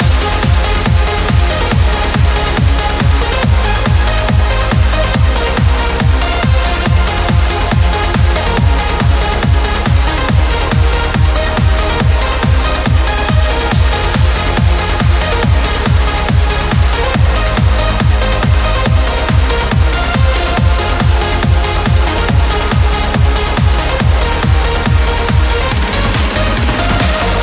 uplifting trance
Genere: trance, uplifting trance